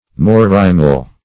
Search Result for " morrimal" : The Collaborative International Dictionary of English v.0.48: Morrimal \Mor"ri*mal\, n. & a. See Mormal .